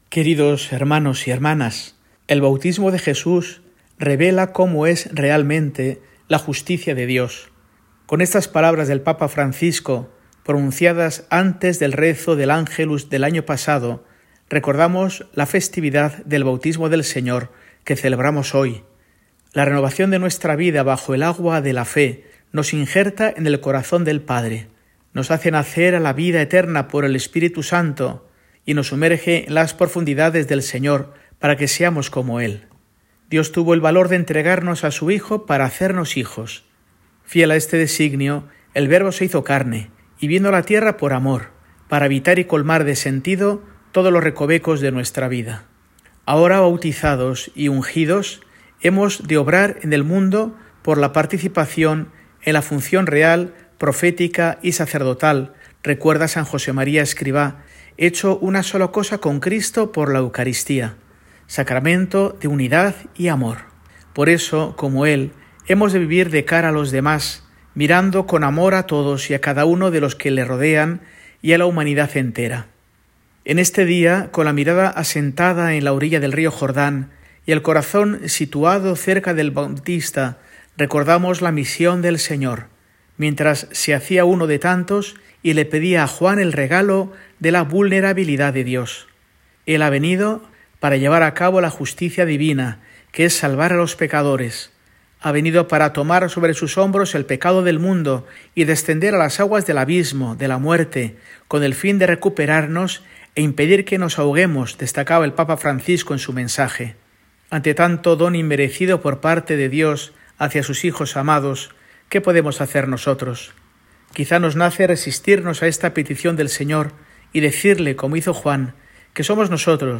Mensaje semanal de Mons. Mario Iceta Gavicagogeascoa, arzobispo de Burgos, para el domingo, 12 de enero de 2025, fiesta del Bautismo del Señor